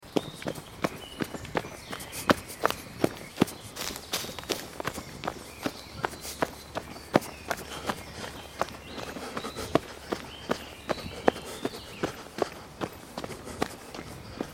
دانلود آهنگ جنگل 14 از افکت صوتی طبیعت و محیط
دانلود صدای جنگل 14 از ساعد نیوز با لینک مستقیم و کیفیت بالا
جلوه های صوتی